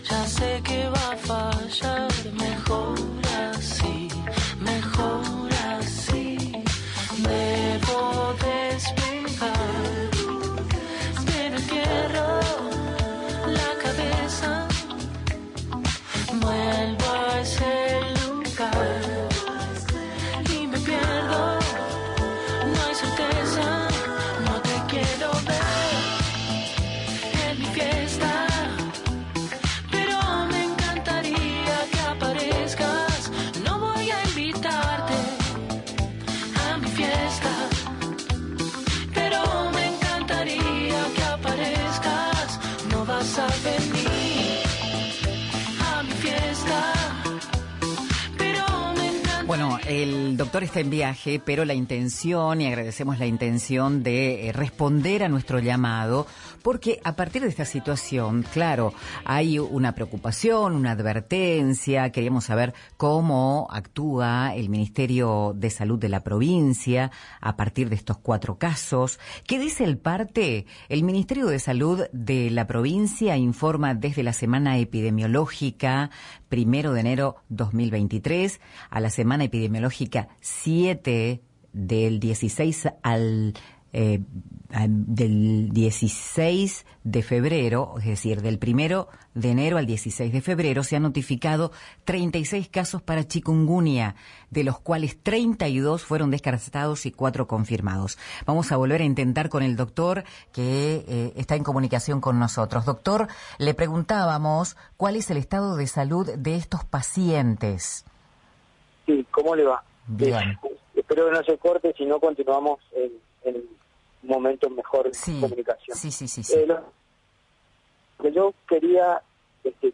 Esta mañana el Ministerio de Salud Pública de Misiones confirmó cuatro casos de fiebre chikungunya en la provincia: dos en Posadas y dos en Puerto Iguazú. En FM 89.3 Santa María de las Misiones entrevistaron